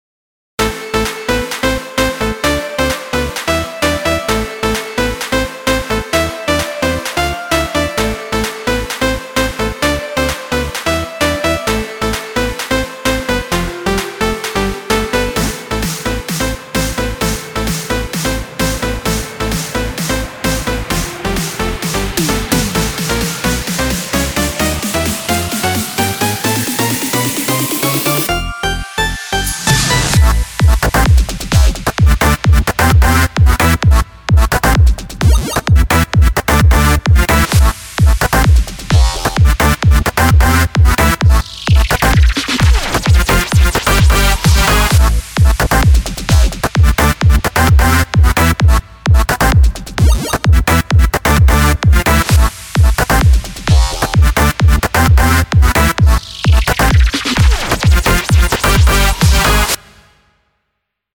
מקצבים שבניתי על קורג.
מוזיקת ראפ…
שימו אוזניות - אחלה בסים של FL סטודיו.